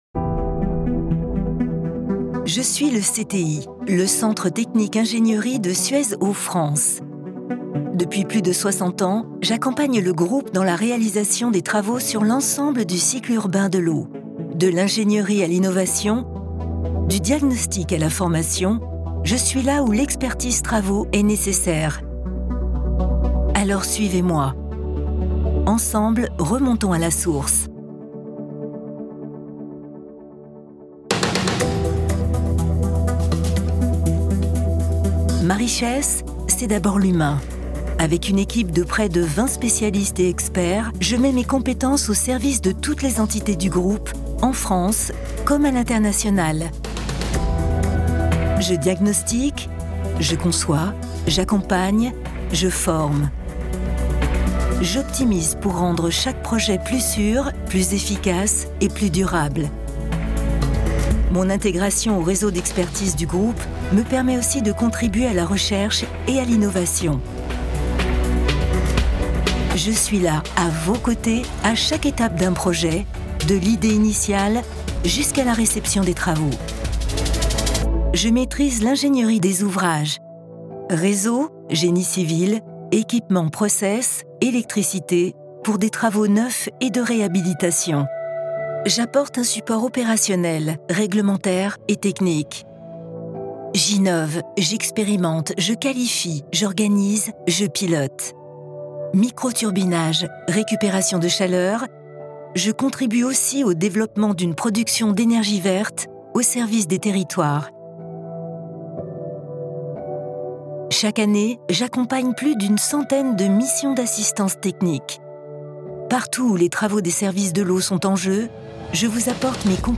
Voix-off femme